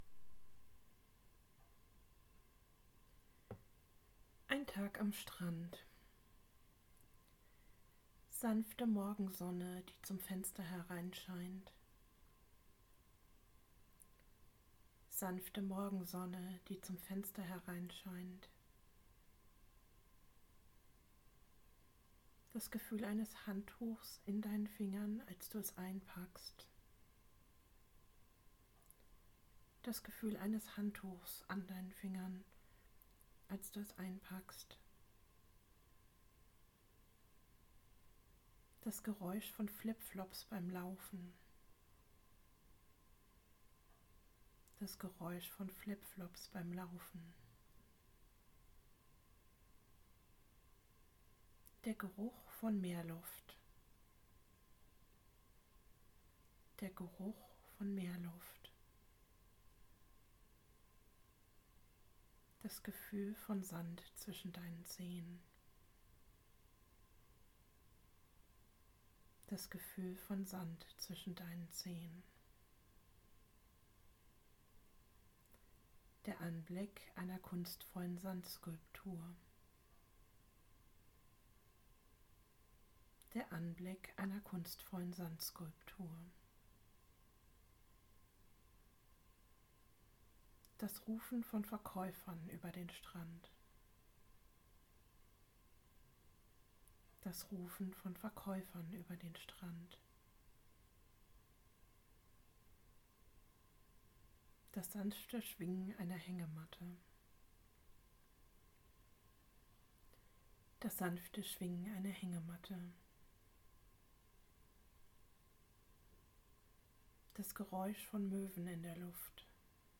In dieser Kurzversion bekommt ihr Anleitung, euch eine Sinneserfahrung nach der anderen vorzustellen. Die Anleitung wird zweimal wiederholt und dann werdet ihr direkt zum nächsten Bild oder Sinn übergeleitet.